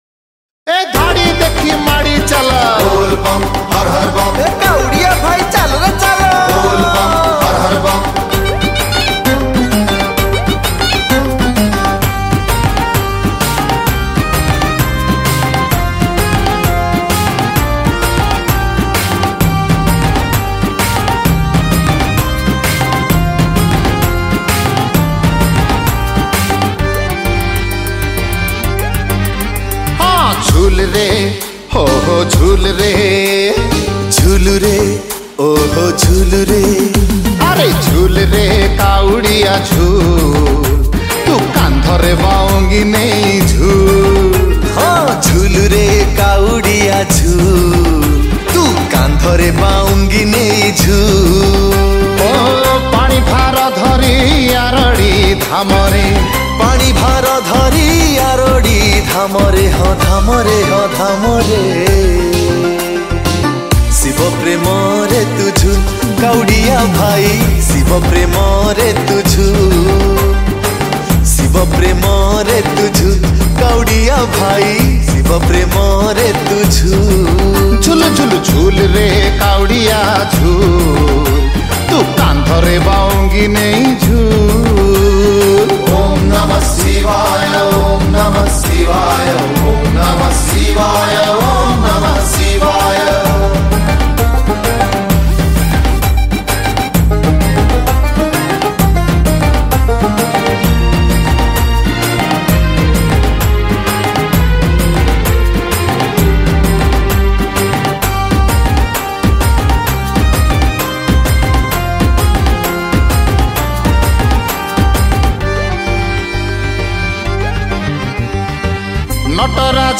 Category : Bolbum Special Song